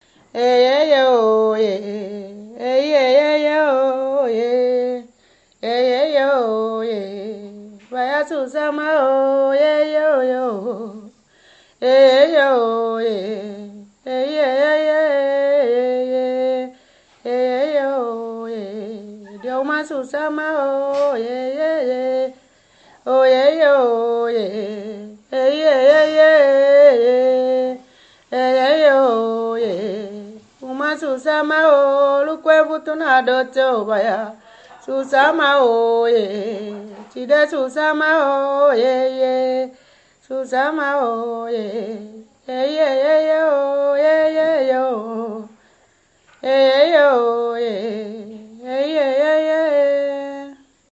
danse : susa (aluku)
Pièce musicale inédite